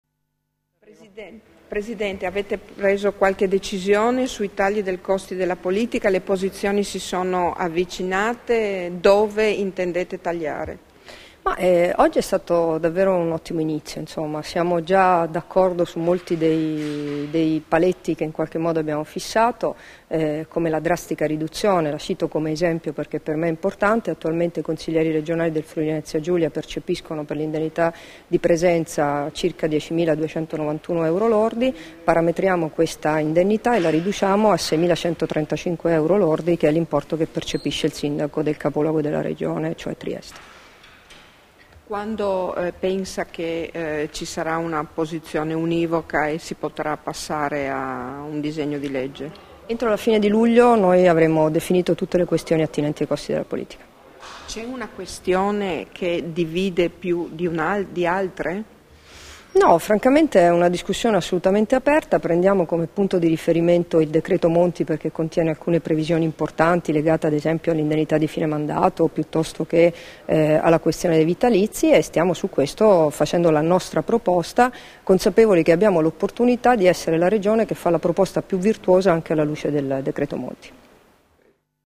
Ascolta le dichiarazioni di Debora Serracchiani (Formato MP3) [1316KB]
rilasciate a margine del tavolo per il taglio dei costi della politica, a Udine il 26 giugno 2013